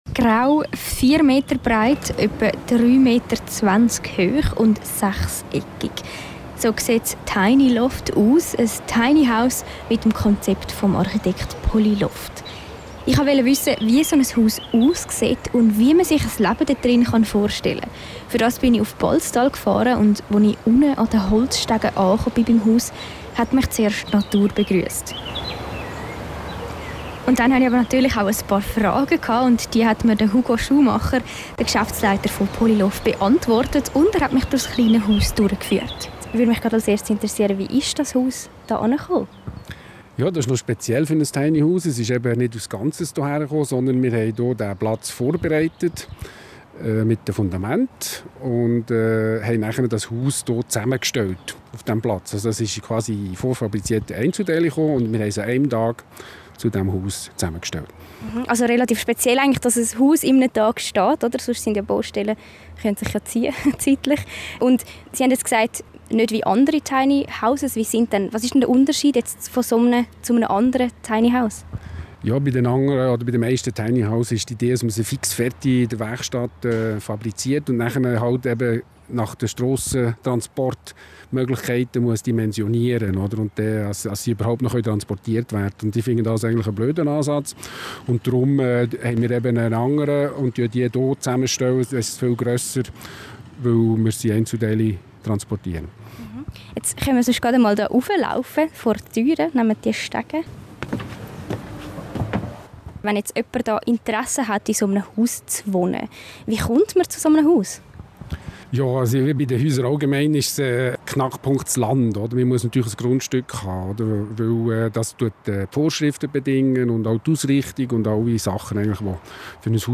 reportage-tiny-house